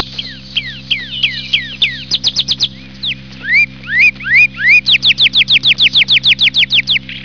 short ringing Zil sesi
Sound Effects